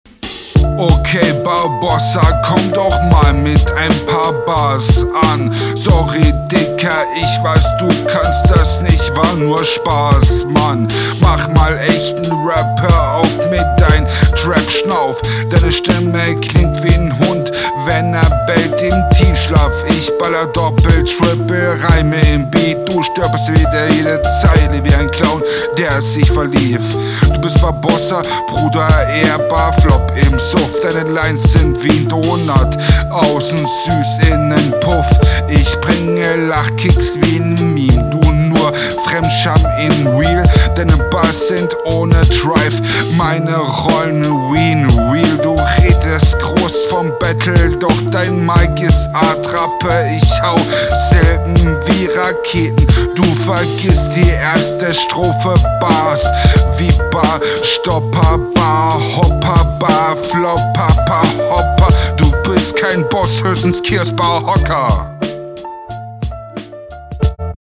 Flow bestenfalls mid, Mix meh, Punches langweilig, Reime kaum vorhanden
Abgehackter Halftime.